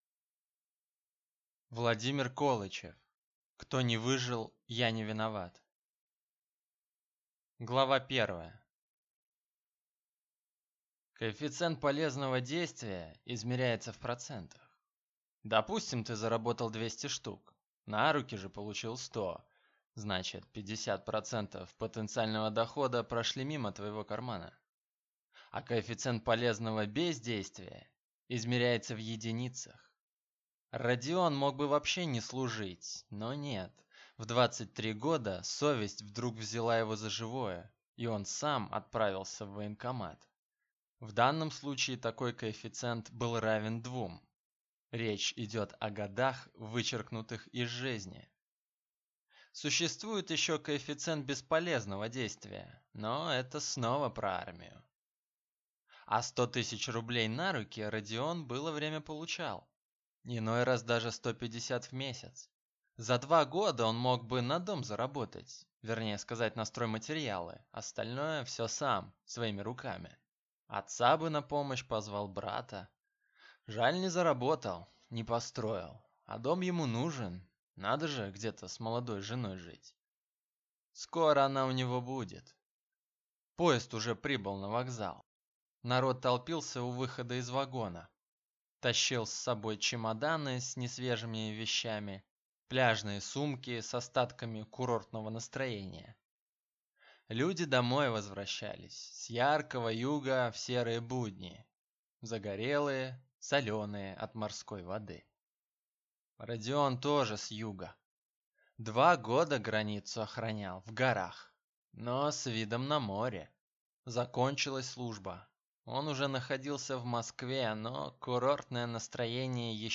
Аудиокнига Кто не выжил, я не виноват | Библиотека аудиокниг